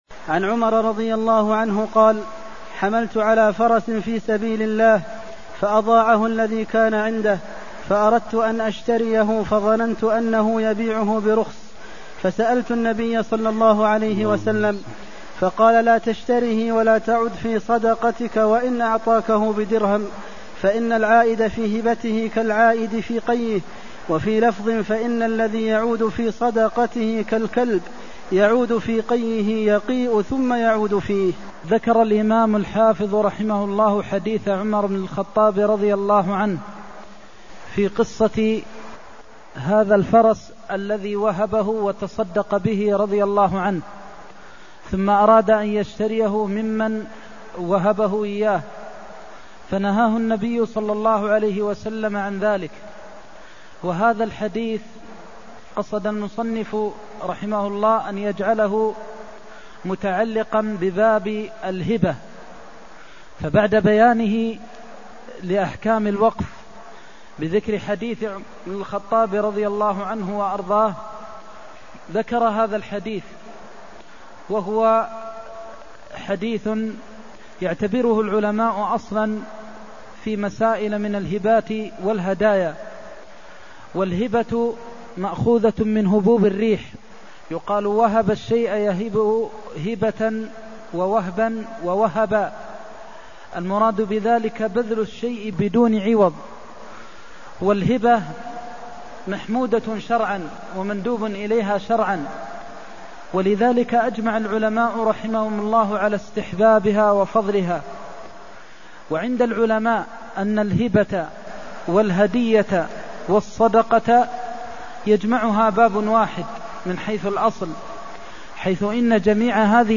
المكان: المسجد النبوي الشيخ: فضيلة الشيخ د. محمد بن محمد المختار فضيلة الشيخ د. محمد بن محمد المختار العائد في هبته كالعائد في قيئه (270)